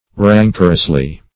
rancorously - definition of rancorously - synonyms, pronunciation, spelling from Free Dictionary Search Result for " rancorously" : The Collaborative International Dictionary of English v.0.48: Rancorously \Ran"cor*ous*ly\, adv.